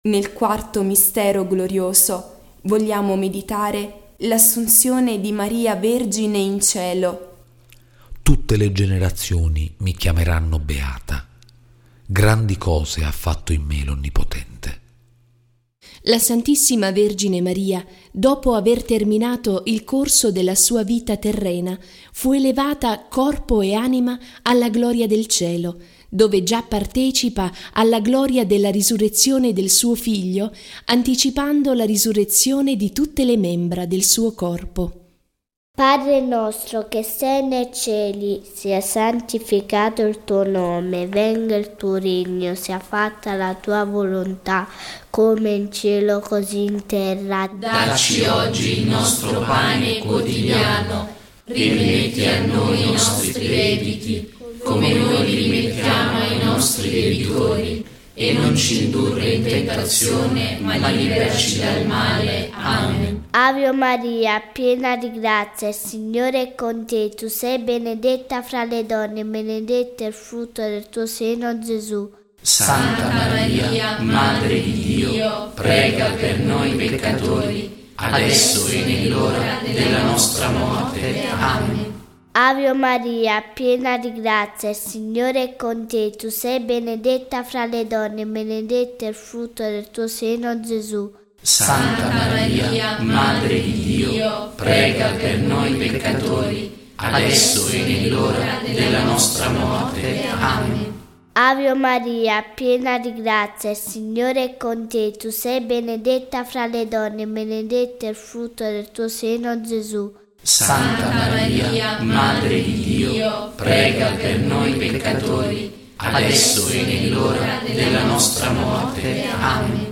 Recitiamo i misteri gloriosi del rosario